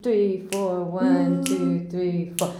COUNT IN.wav